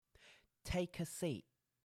take a seat – /teɪ.kə.siːʔ/